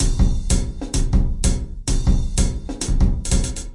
描述：奇数时间节拍120bpm
Tag: 回路 常规 时间 节奏 120BPM 节拍 敲击循环 量化 鼓环 有节奏